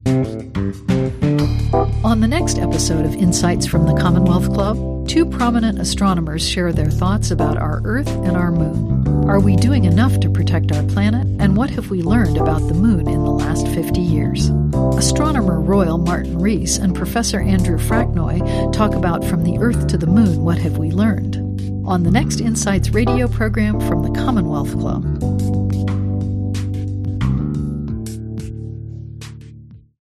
Ep003 Promo :30